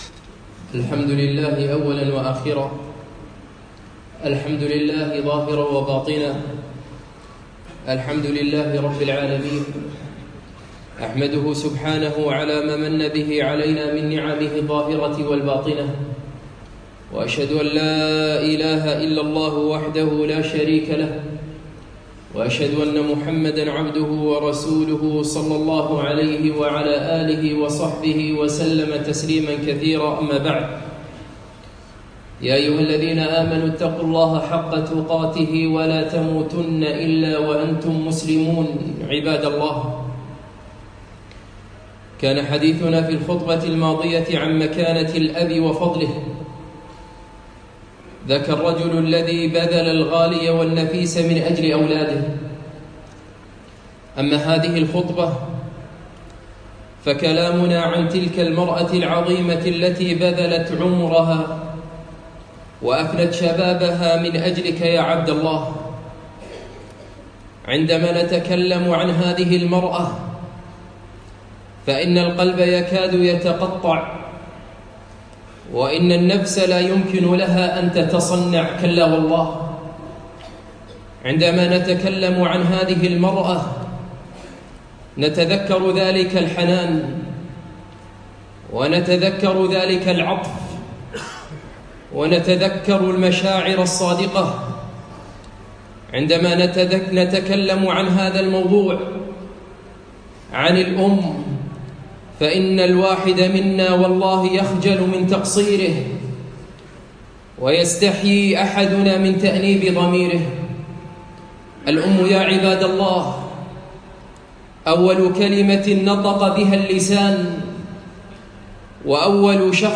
خطبة أحق الناس بالبر